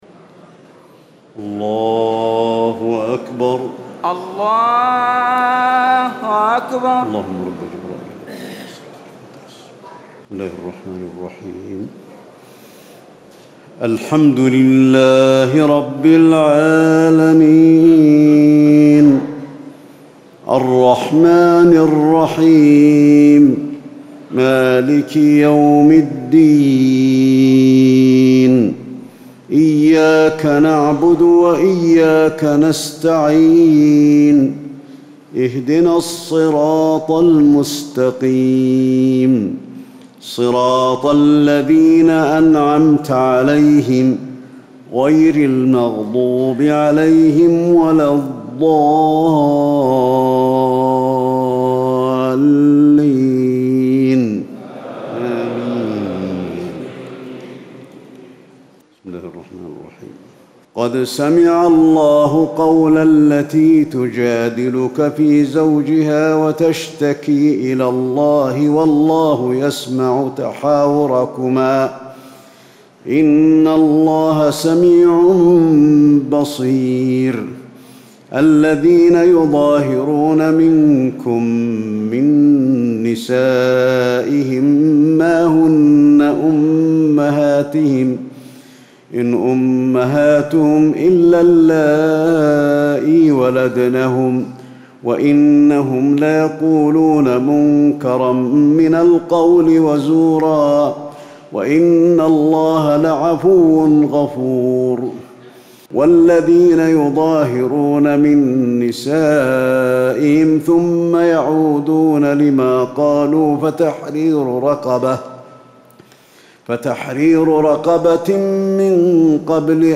تراويح ليلة 27 رمضان 1437هـ من سورة المجادلة الى الصف Taraweeh 27 st night Ramadan 1437H from Surah Al-Mujaadila to As-Saff > تراويح الحرم النبوي عام 1437 🕌 > التراويح - تلاوات الحرمين